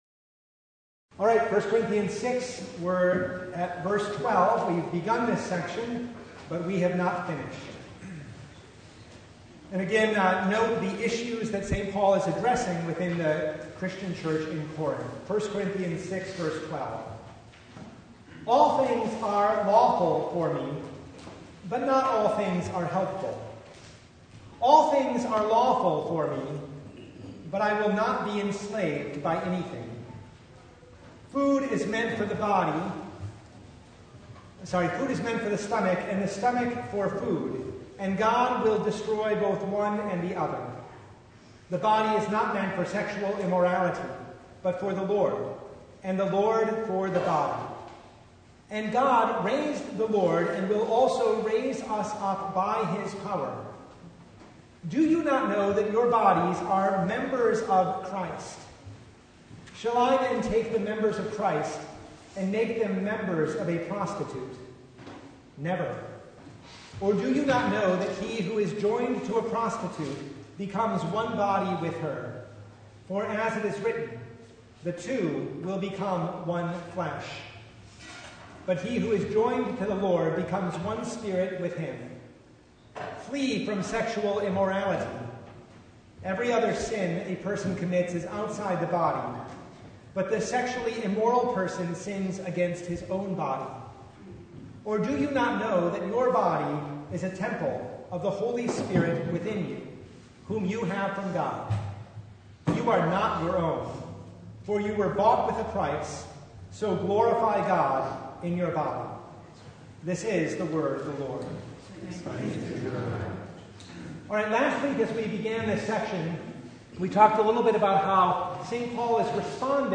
1 Corinthians 6:12-20 Service Type: Bible Hour Topics: Bible Study « The Fifth Sunday in Lent